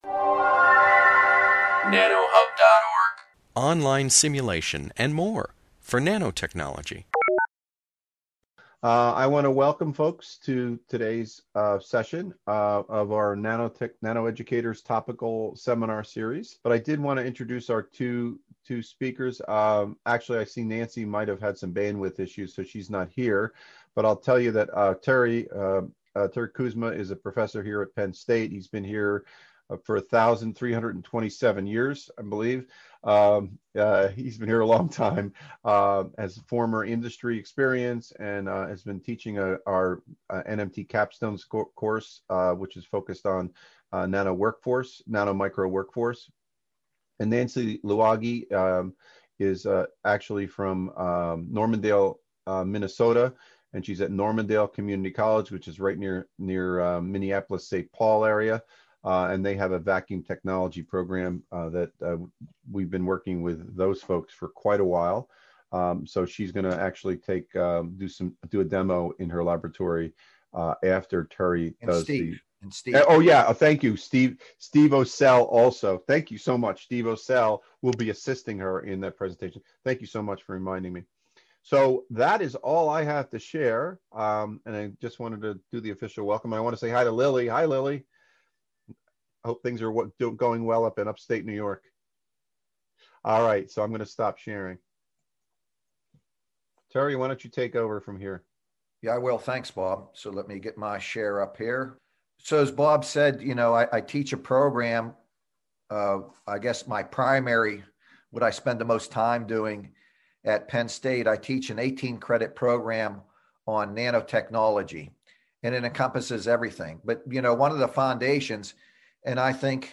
This webinar, published by the Nanotechnology Applications and Career Knowledge Support (NACK) Center at Pennsylvania State University, focuses on teaching vacuum systems and vacuum technology as a foundation for nanotechnology education.